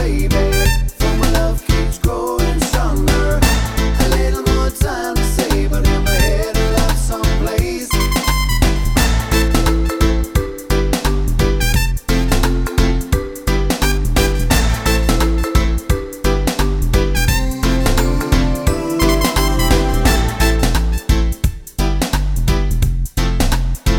no Backing Vocals Reggae 3:32 Buy £1.50